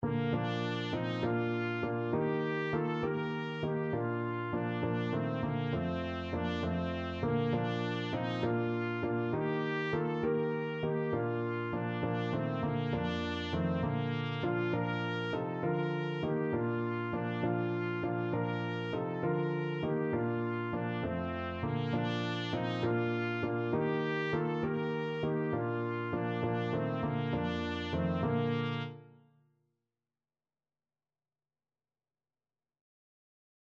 6/8 (View more 6/8 Music)
Classical (View more Classical Trumpet Music)